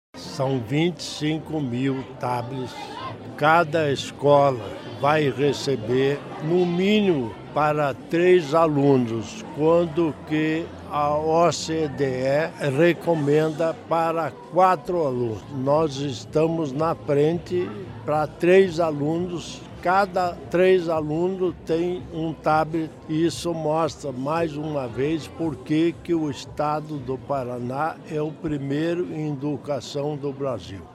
Sonora do vice-governador Darci Piana sobre a entrega de 25 mil tablets para escolas estaduais